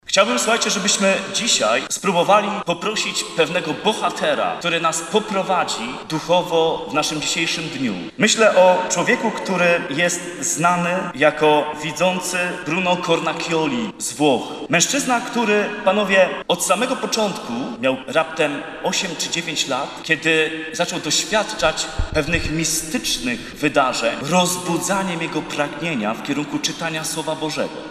Wspólną modlitwę rozpoczęła msza święta w Bazylice Archikatedralnej św. Jana Chrzciciela na Starym Mieście.